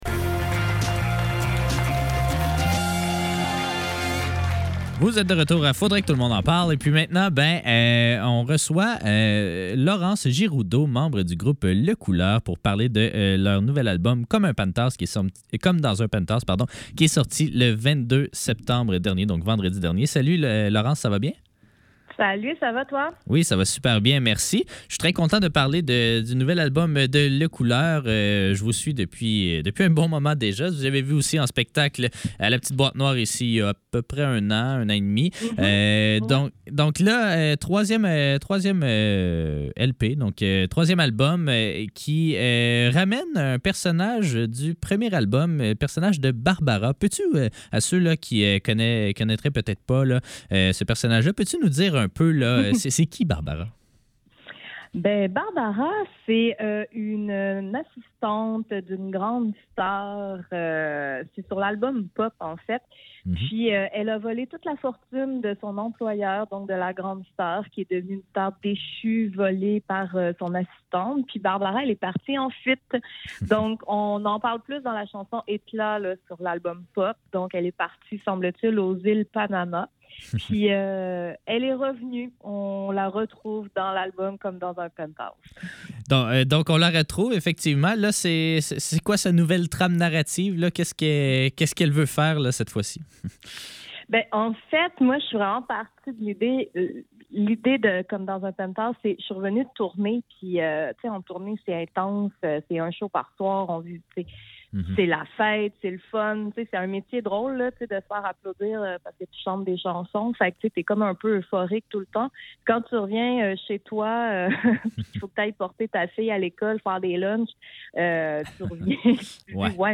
Faudrait que le tout l'monde en parle - Entrevue